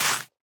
Minecraft Version Minecraft Version snapshot Latest Release | Latest Snapshot snapshot / assets / minecraft / sounds / block / sweet_berry_bush / place5.ogg Compare With Compare With Latest Release | Latest Snapshot